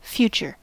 Ääntäminen
Synonyymit coming Ääntäminen US RP : IPA : /ˈfjuːtʃə/ US : IPA : /ˈfjuːtʃɚ/ Lyhenteet ja supistumat (kielioppi) fut.